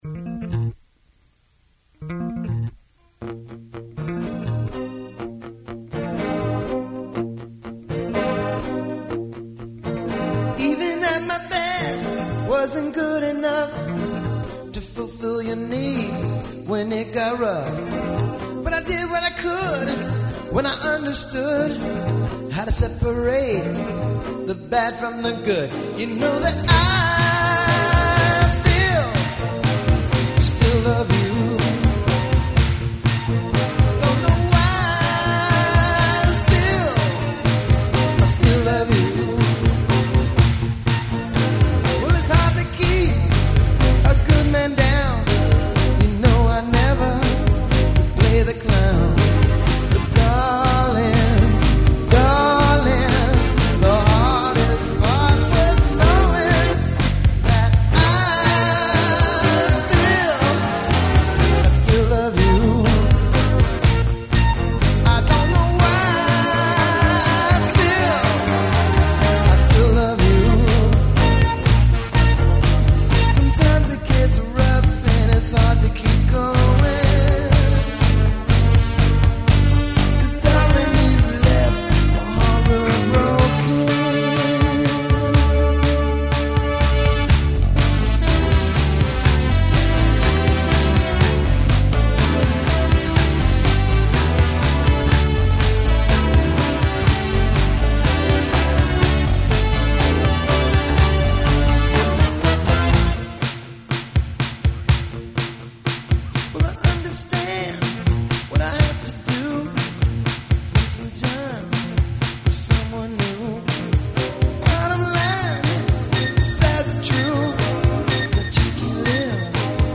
Vocals, Guitars, Bass, Drum Machine
Keyboards, Backing Vocals